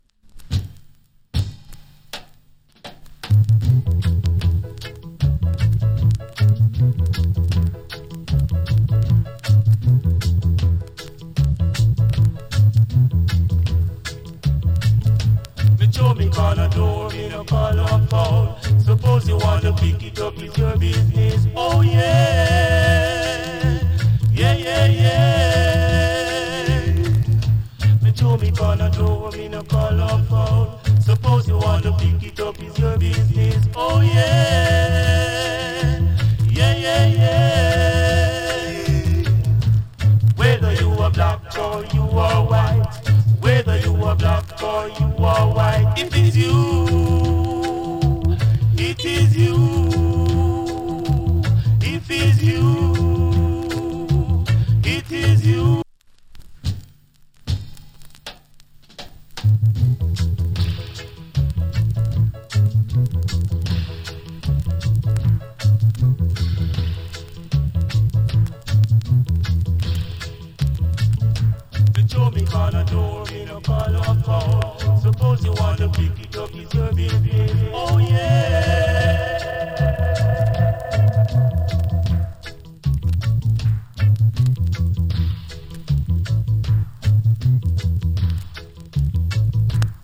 チリ、ジリノイズ少々有り。